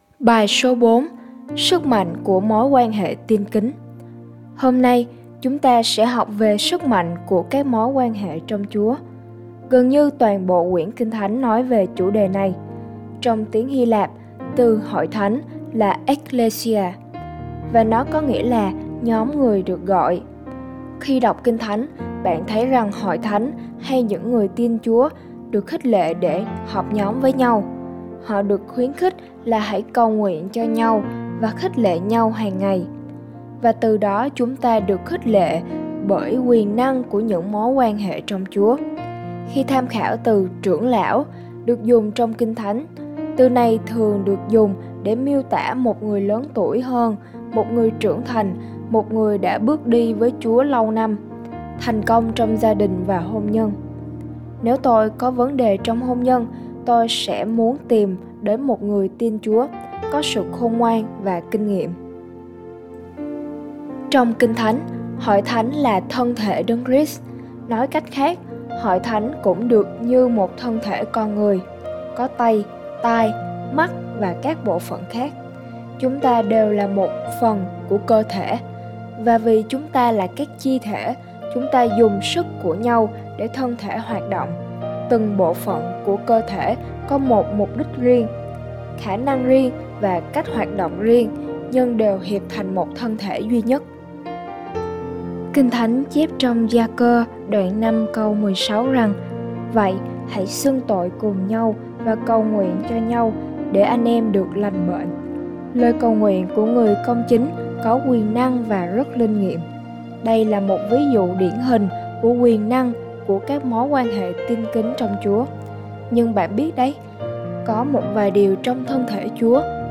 BÀI HỌC